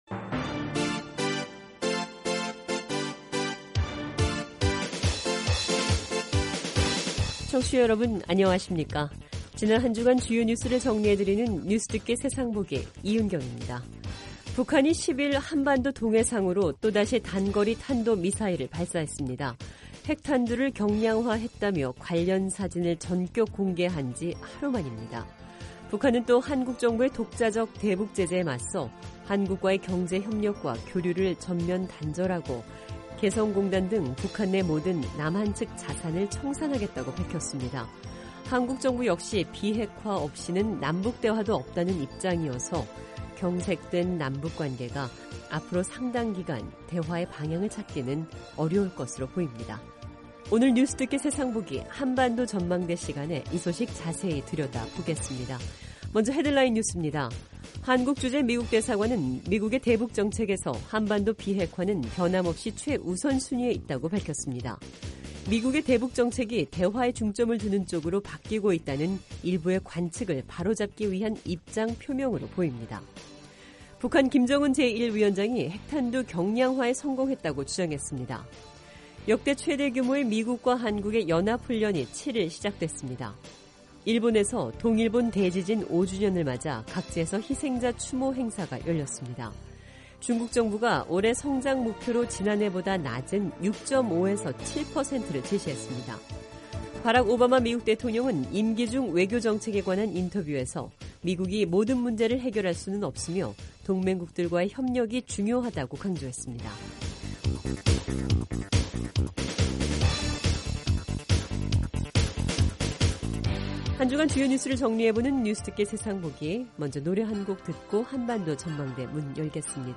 지난 한주간 주요 뉴스를 정리해 드리는 뉴스듣기 세상보기 입니다. 북한이 10일 한반도 동해 상으로 또다시 단거리 탄도미사일을 발사했습니다.